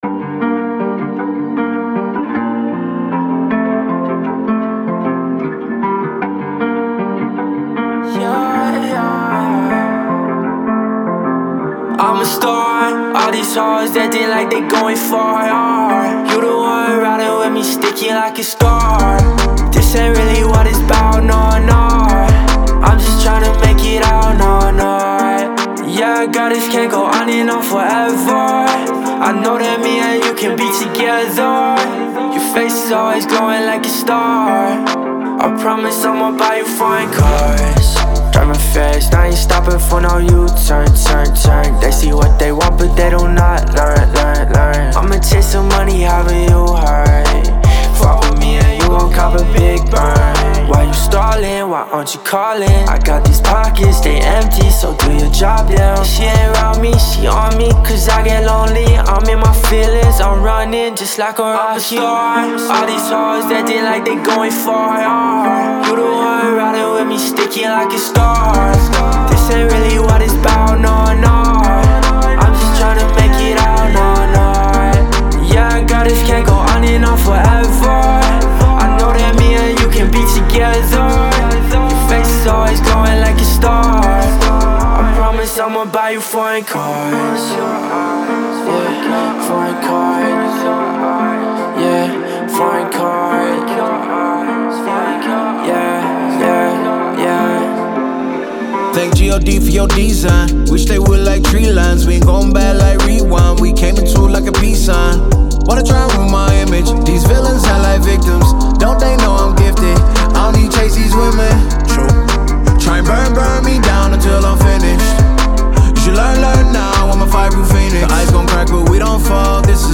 ژانرهای :  هیپ هاپ / رپ